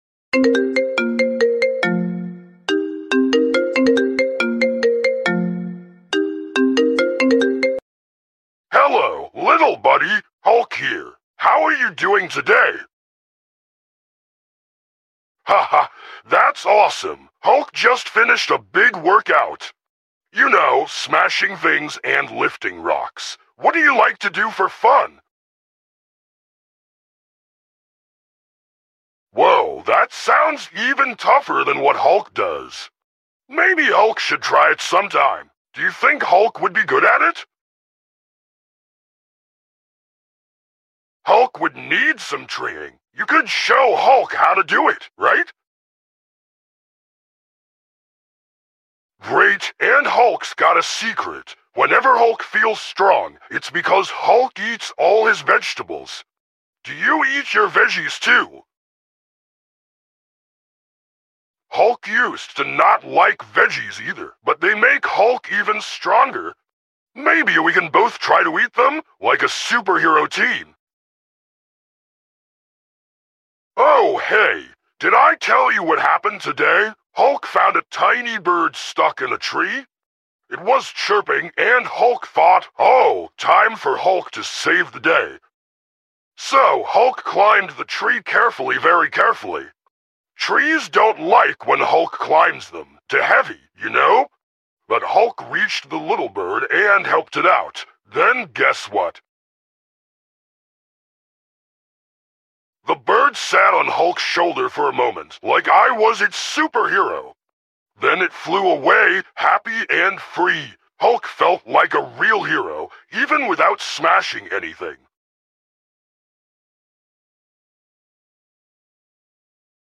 💚💥 What happens when you get a fake call from the Incredible Hulk?! 😱😂 He’s sharing his hero secrets, workout tips, and even saving a tiny bird! But when things get too exciting… HULK SMASHES the phone!